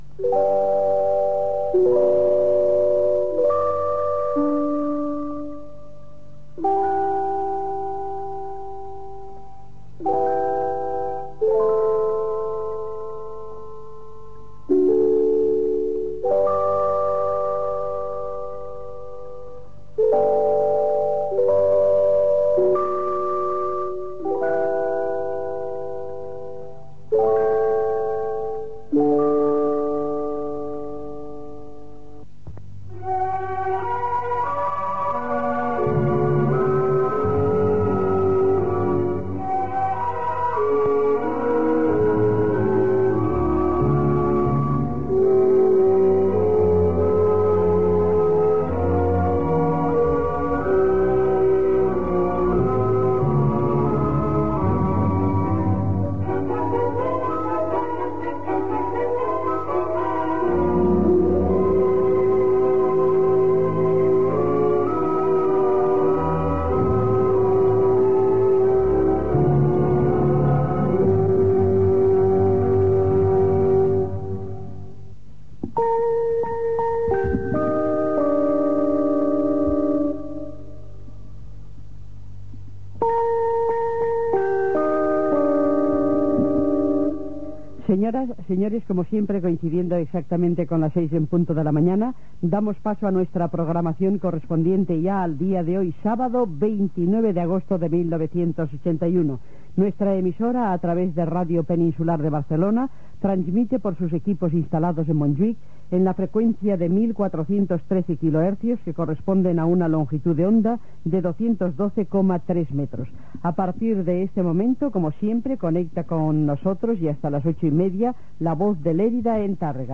Sintonia de l'emissora, música, indicatiu, inici de l'emissió amb la data, freqüència i saradana